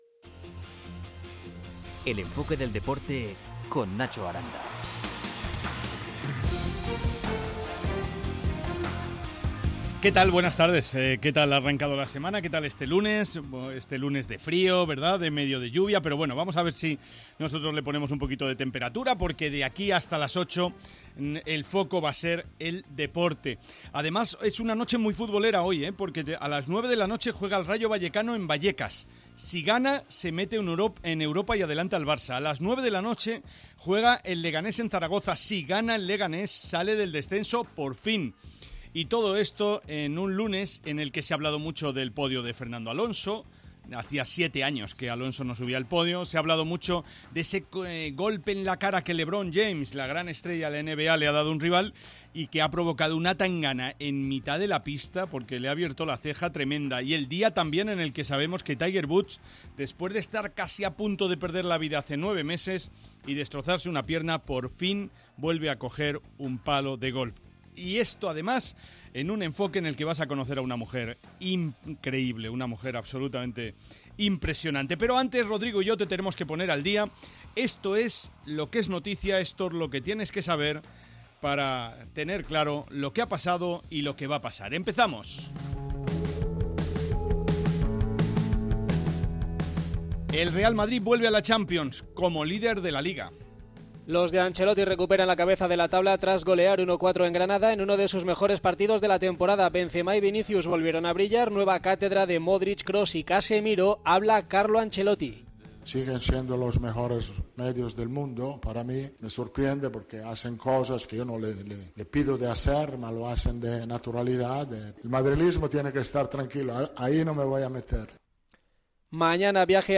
De todo ello, y de la cita de la Liga de Campeones para Atlético de Madrid y Real Madrid hablamos en nuestra mesa de análisis.